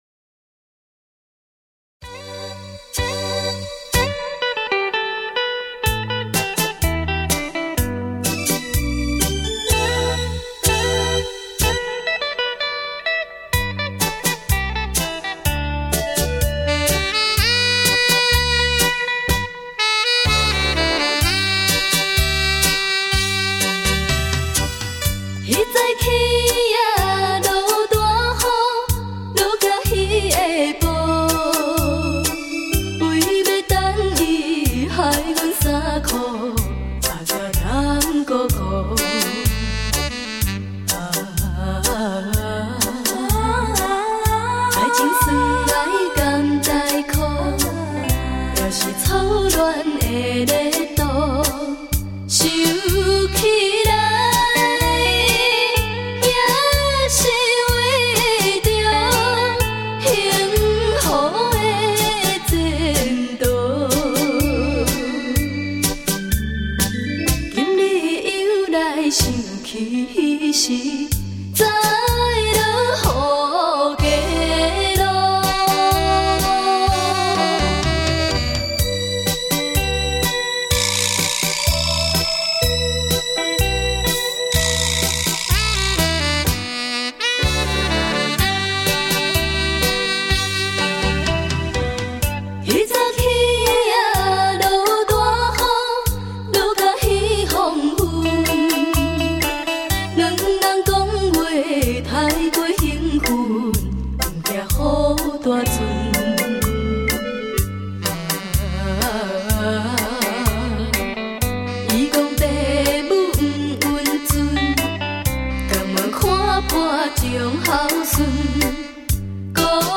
台语CD